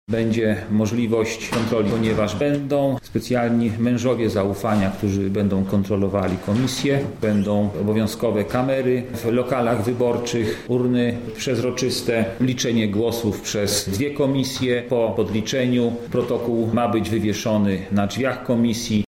O tym jakie zmiany mogą dotknąć wyborców mówi senator Andrzej Stanisławek.